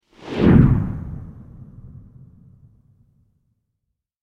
دانلود آهنگ رعدو برق 20 از افکت صوتی طبیعت و محیط
دانلود صدای رعدو برق 20 از ساعد نیوز با لینک مستقیم و کیفیت بالا
جلوه های صوتی